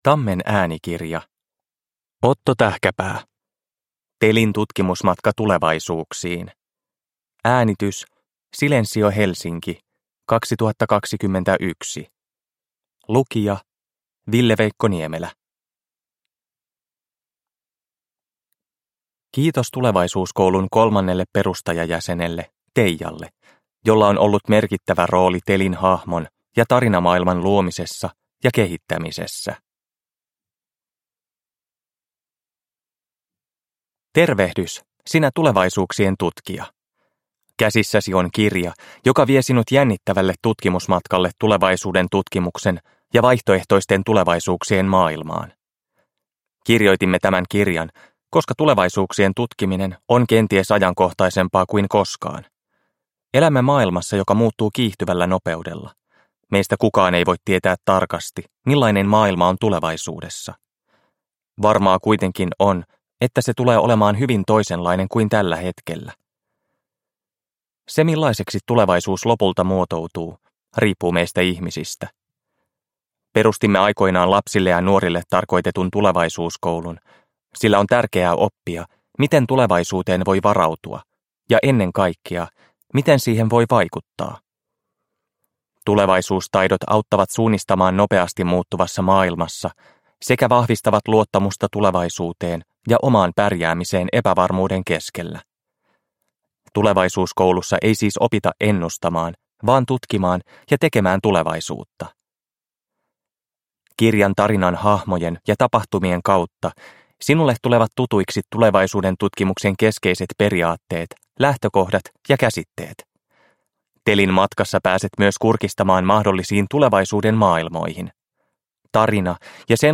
Telin tutkimusmatka tulevaisuuksiin – Ljudbok – Laddas ner